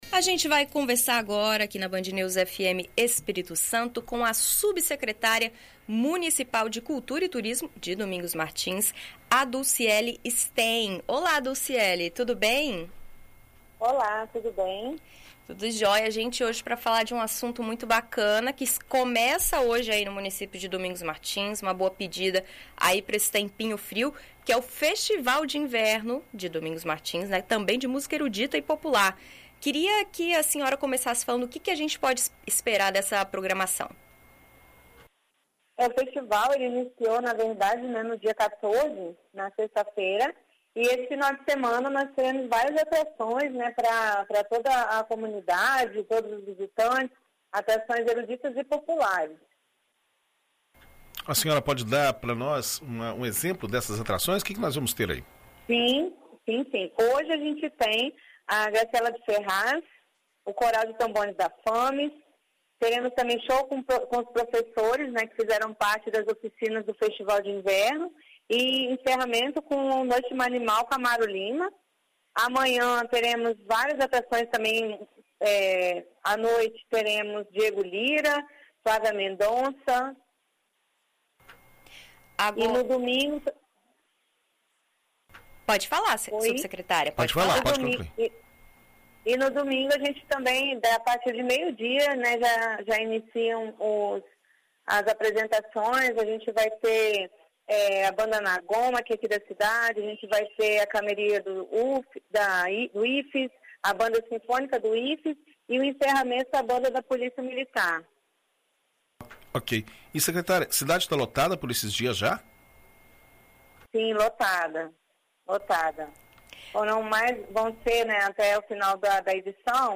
Em entrevista à BandNews FM ES, nesta sexta-feira (21), a Subsecretária Municipal de Cultura e Turismo, Dulciele Stein, explica o desenvolvimento do evento.